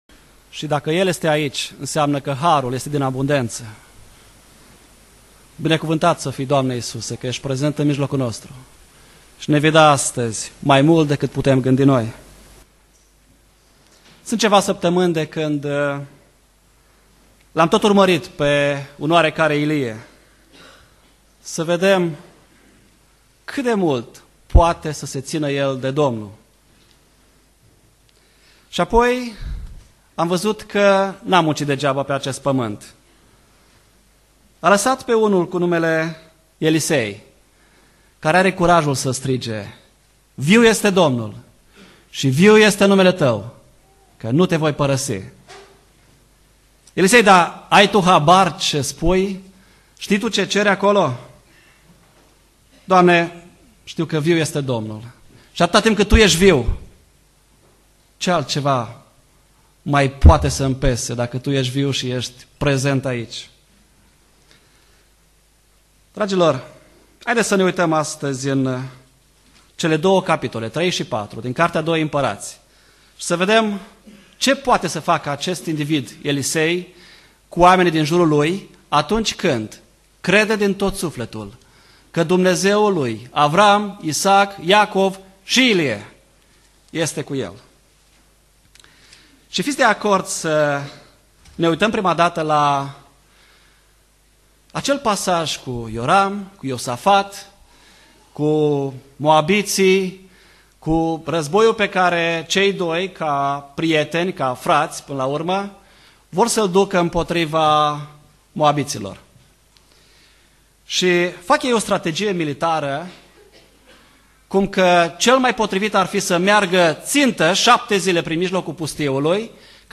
Predica Exegeza 2 Imparati cap. 3-4